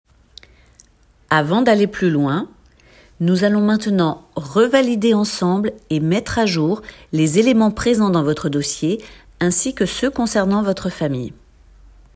Voix off
28 - 45 ans - Mezzo-soprano Soprano